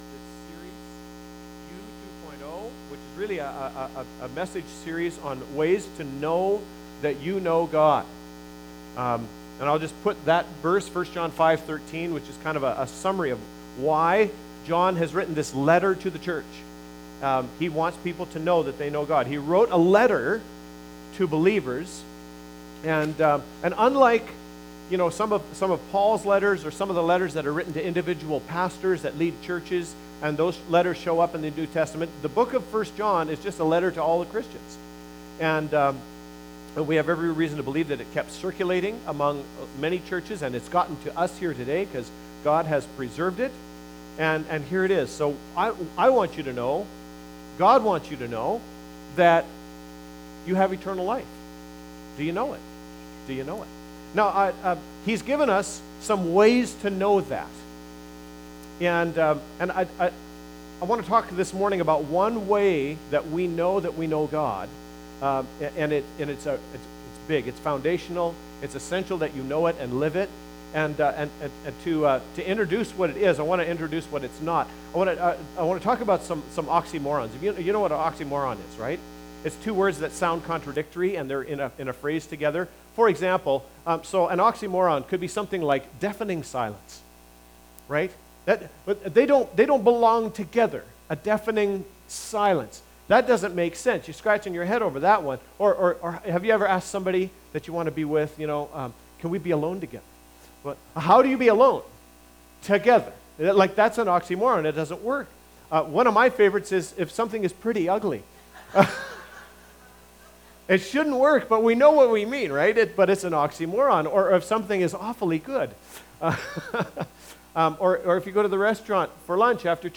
Sermon-1-John-4v7-21.mp3